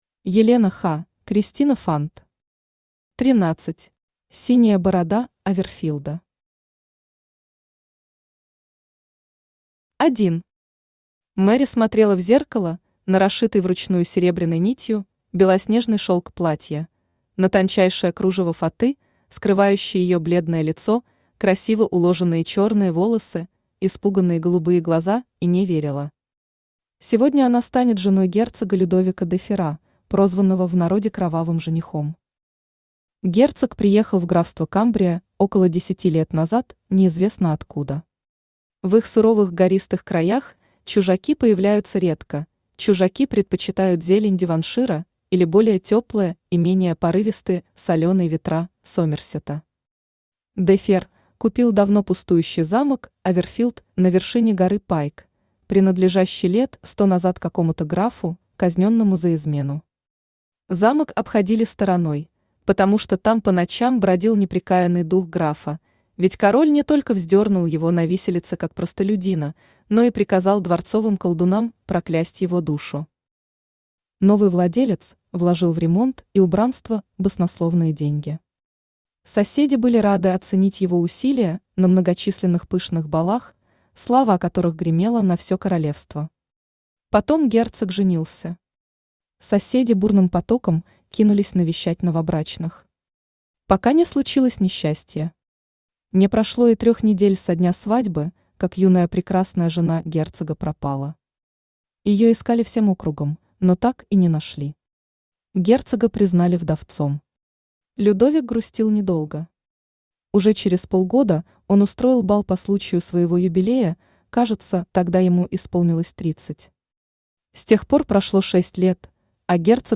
Aудиокнига 13 Автор Елена Ха Читает аудиокнигу Авточтец ЛитРес. Прослушать и бесплатно скачать фрагмент аудиокниги